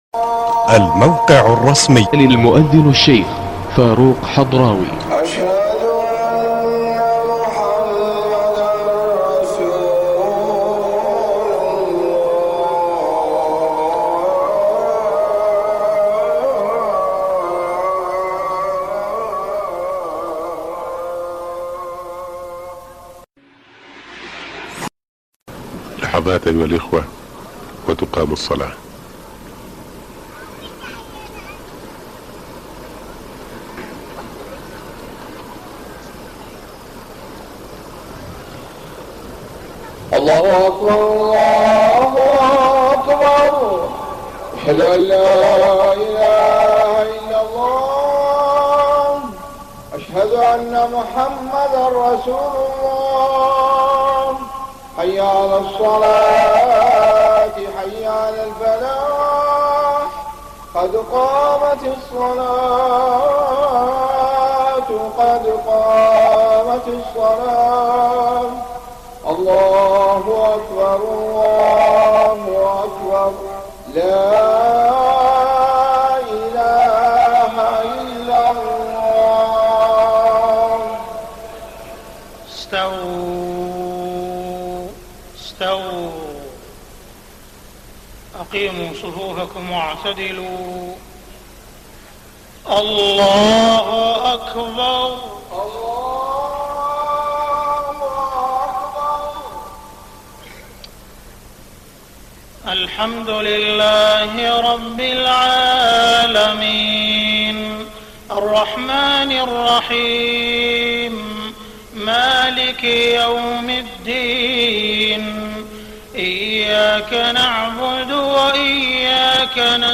صلاة المغرب 20 رمضان 1423هـ من سورتي الأحزاب و الإخلاص > 1423 🕋 > الفروض - تلاوات الحرمين